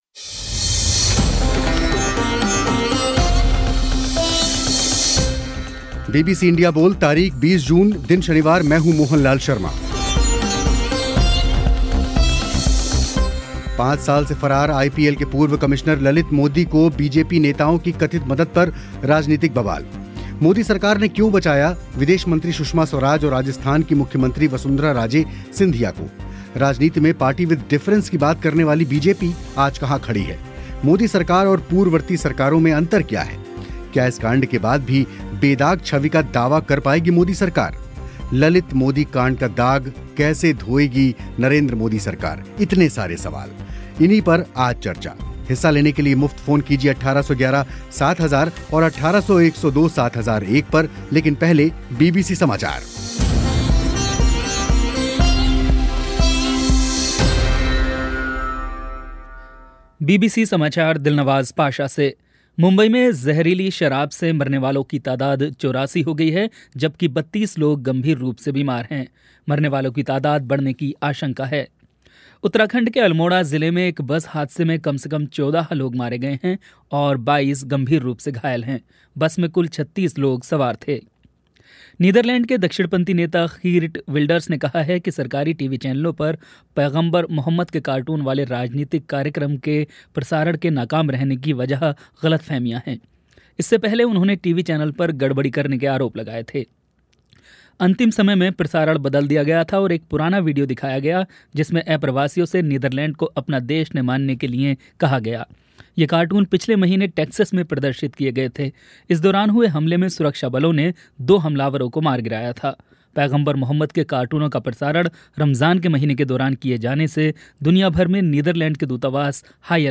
'इंडिया बोल' में इसी विषय पर हुई चर्चा. कार्यक्रम में हिस्सा लिया वरिष्ठ पत्रकार सिद्धार्थ वरदराजन और भारतीय जनता पार्टी के प्रवक्ता सुधांशु त्रिवेदी ने